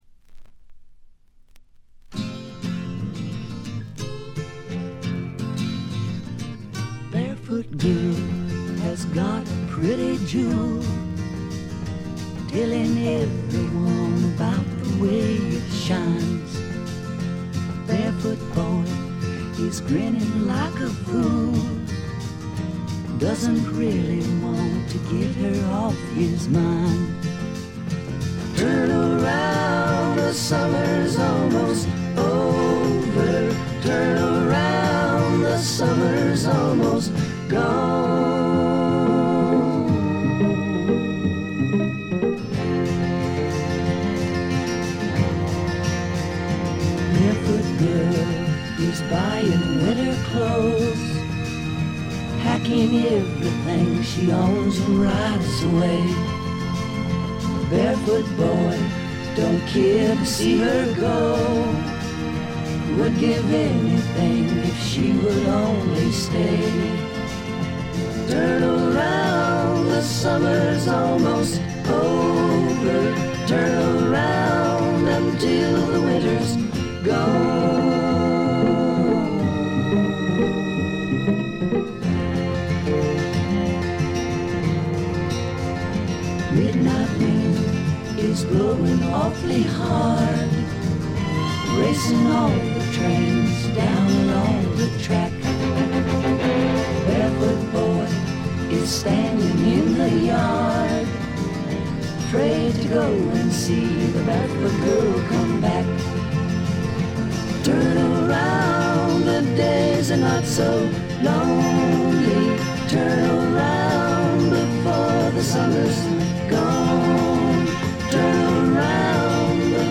ところどころでチリプチ。
試聴曲は現品からの取り込み音源です。